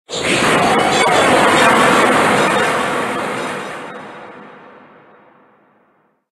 Cri de Duralugon Gigamax dans Pokémon HOME.
Cri_0884_Gigamax_HOME.ogg